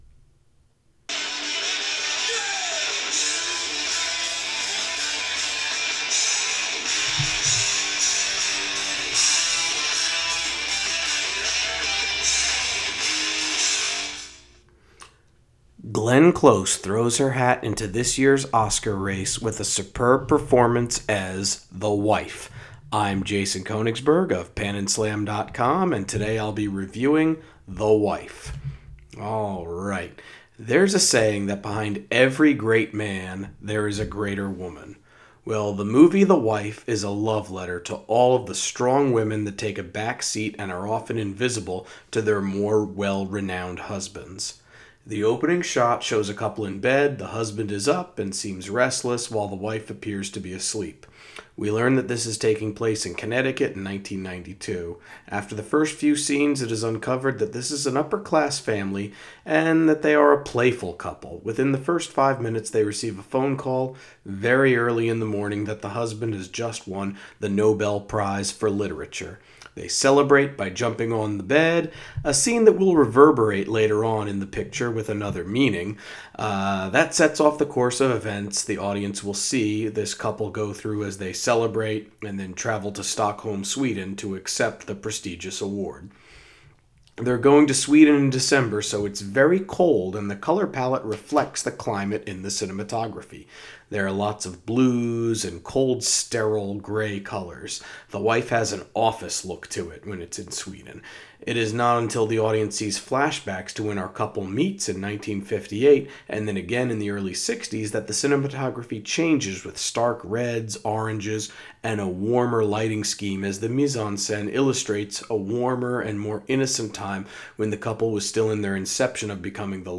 Movie Review: The Wife